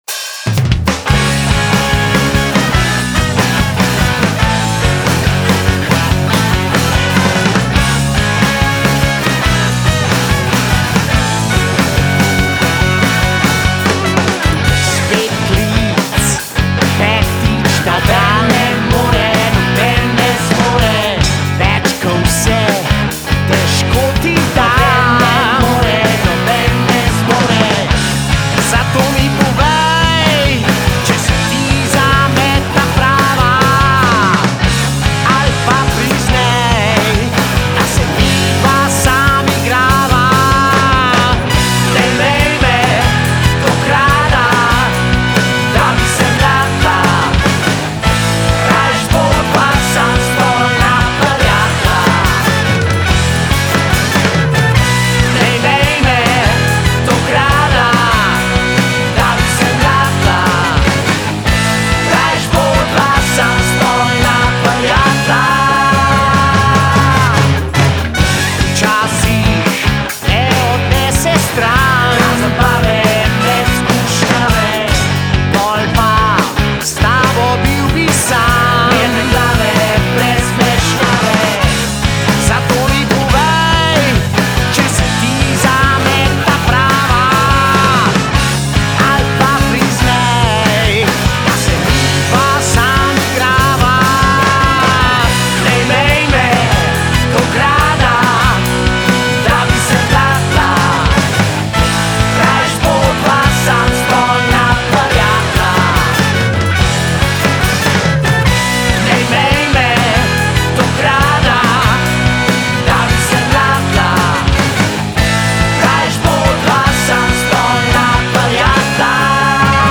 ROCK’N’ROLL
je ustvarjena v pravi rock’n’roll maniri